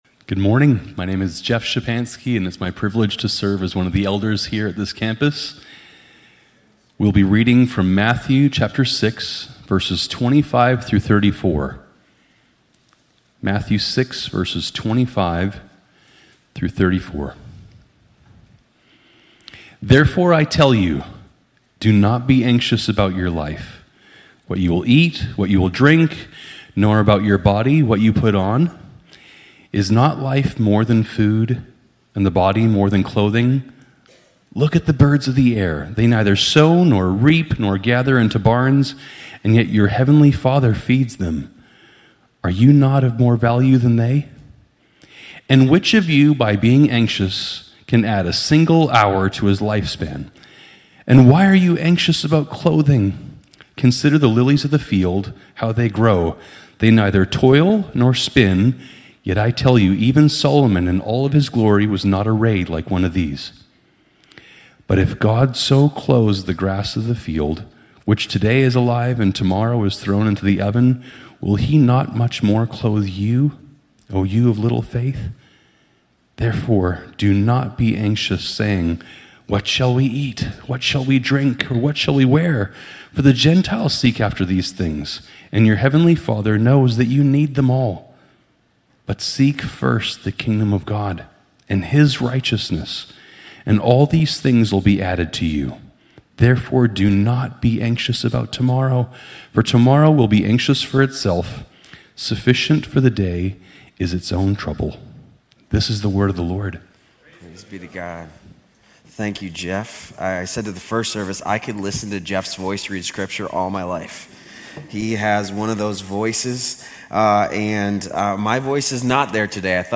Grace Community Church University Blvd Campus Sermons 3_23 University Blvd Campus Mar 24 2025 | 00:31:36 1x 00:00 / 00:31:36 Subscribe Share RSS Feed Share Link Embed